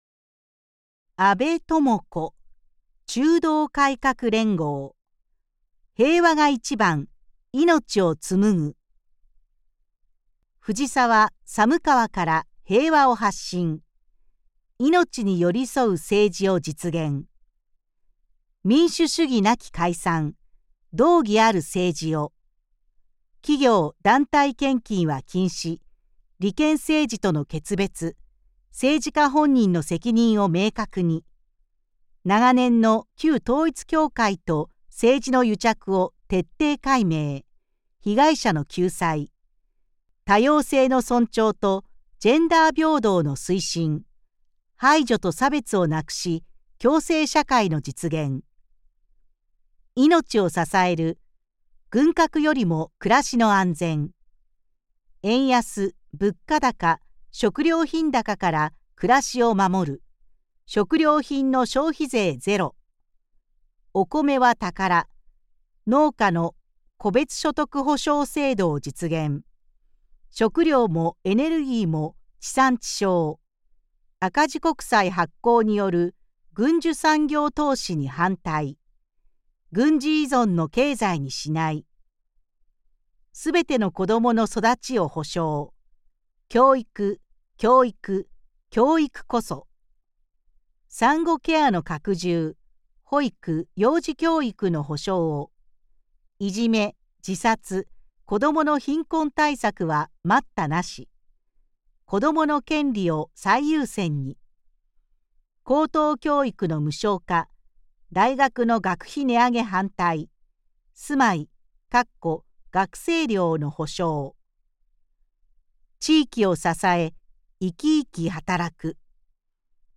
衆議院議員総選挙　候補者・名簿届出政党等情報（選挙公報）（音声読み上げ用）